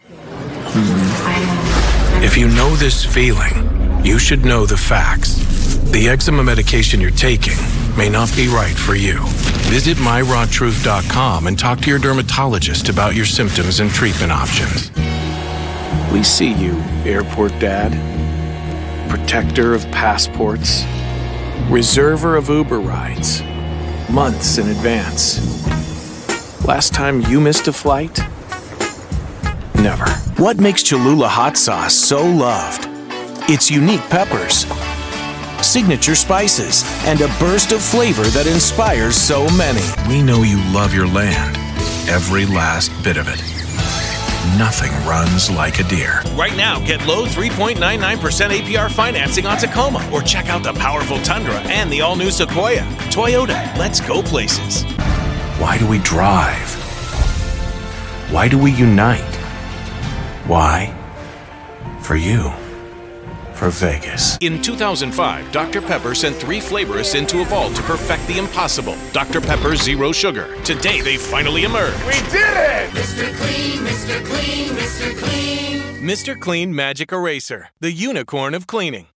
US Cellular Voice Over Commercial Actor + Voice Over Jobs
1208Commercial_Demo-V2.mp3